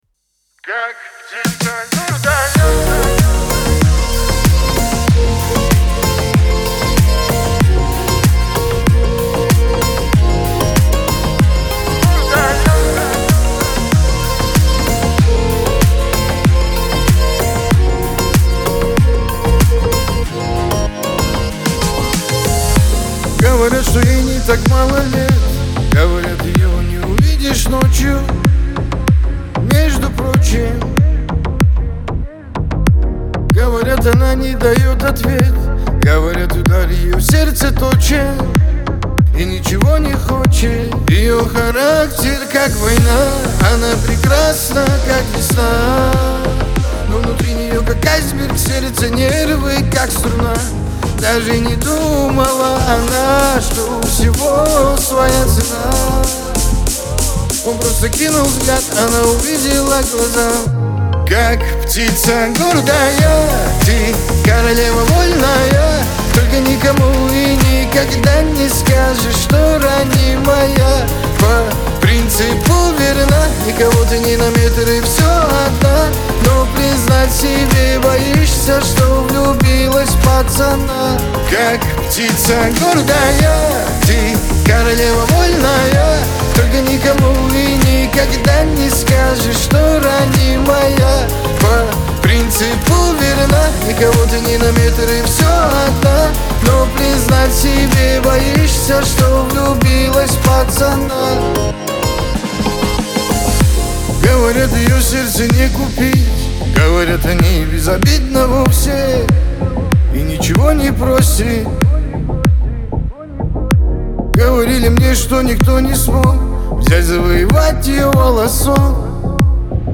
Лирика , Кавказ – поп